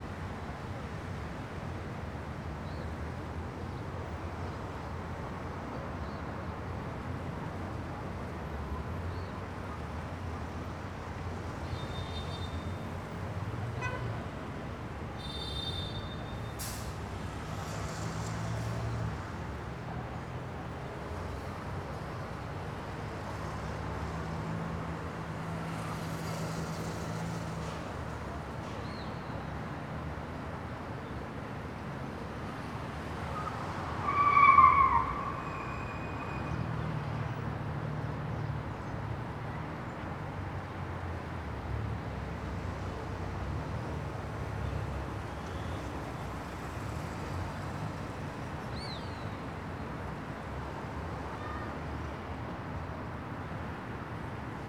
CSC-18-063-LE - Muito transito longe alto do predio goiania algumas buzinas e carro canta pneu no meio.wav